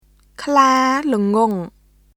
[クラー・ルゴン　kʰlaː lŋʷɔ̀ŋ]